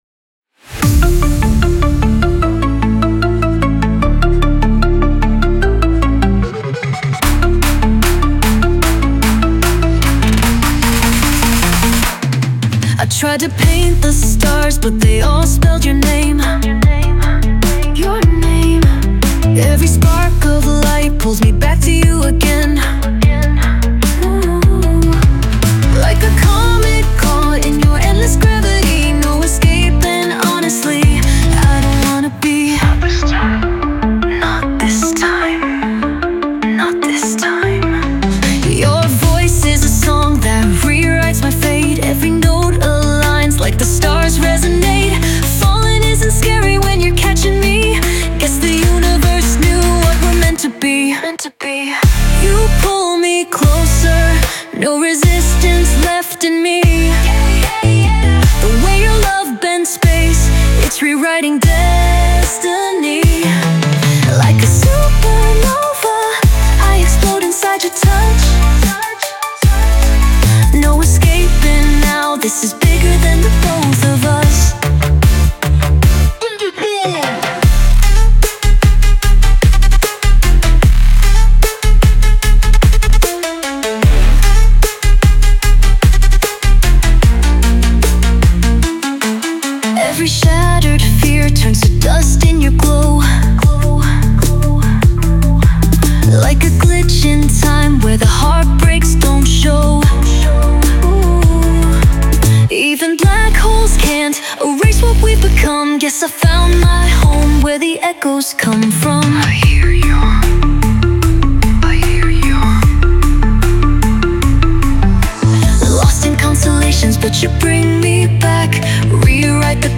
Aggressive Dubstep | Brostep | Melodic Riddim | Bass Music